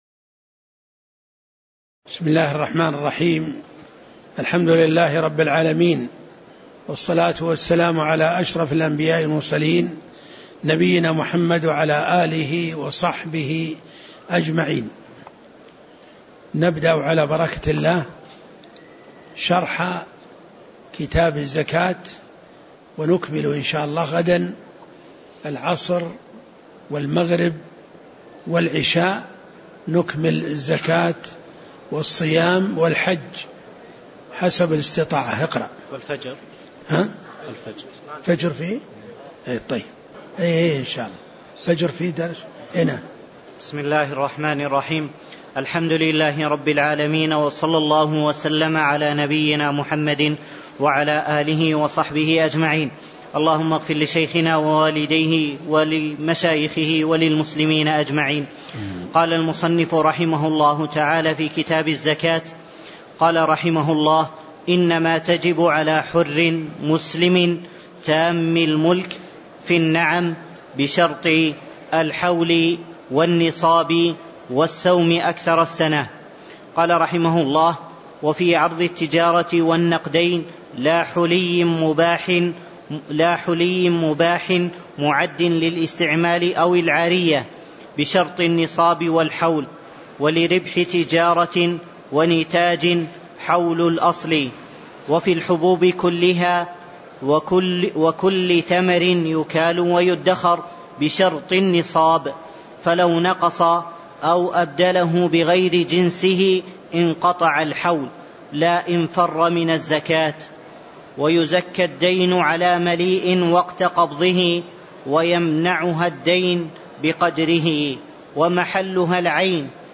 تاريخ النشر ١٧ شوال ١٤٣٩ هـ المكان: المسجد النبوي الشيخ